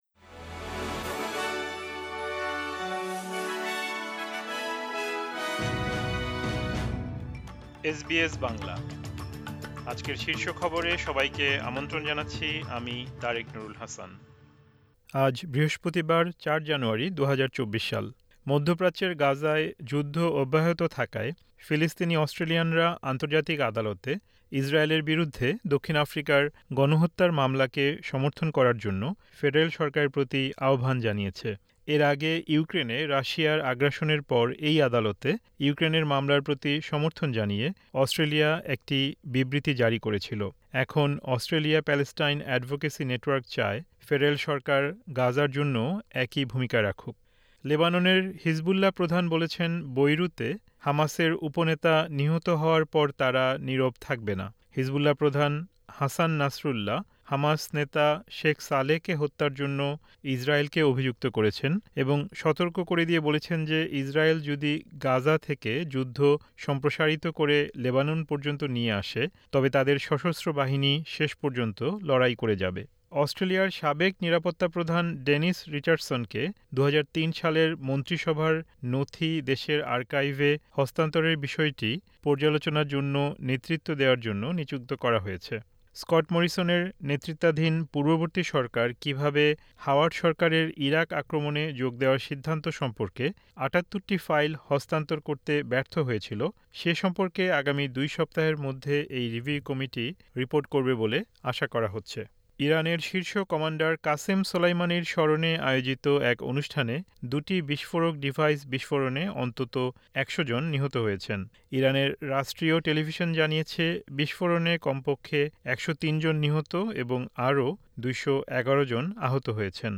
এসবিএস বাংলা শীর্ষ খবর: ৪ জানুয়ারি, ২০২৪